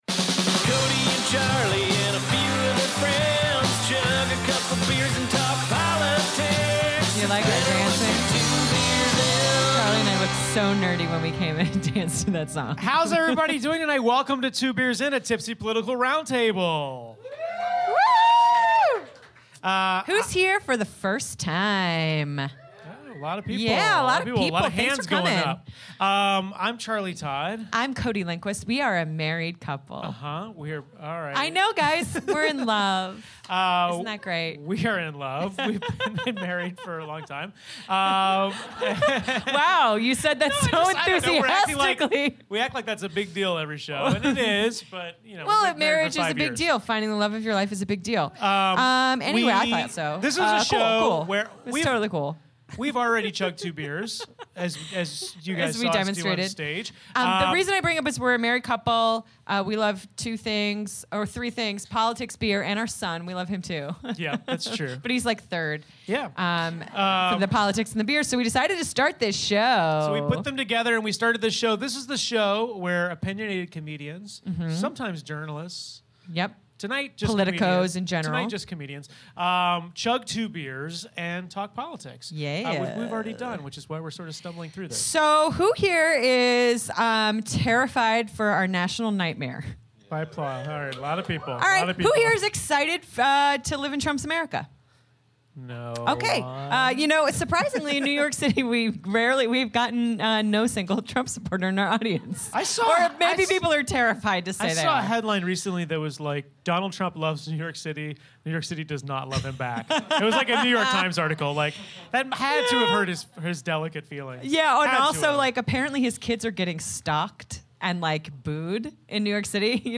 join us for our live show at UCB Theatre East to discuss Rick Perry running the agency he couldn't remember.